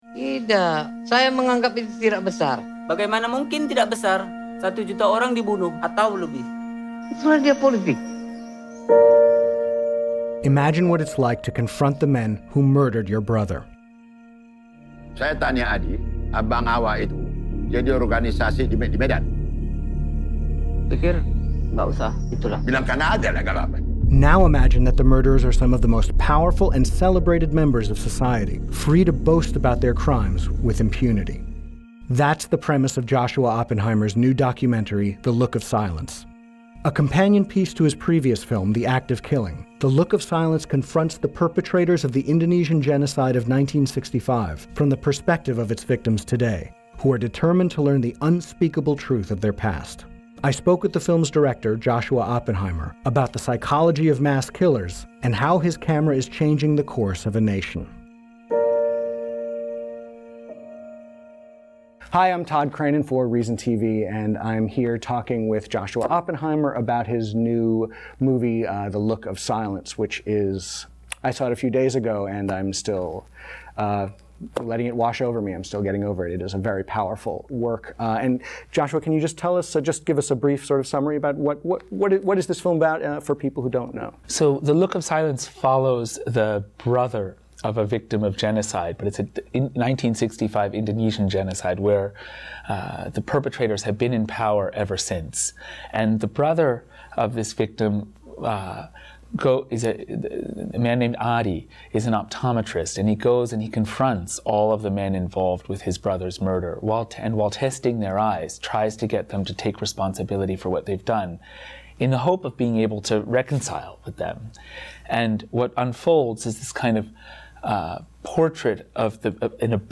Reason TV spoke with Oppenheimer about the psychology of genocide and how his camera is changing the course of a nation.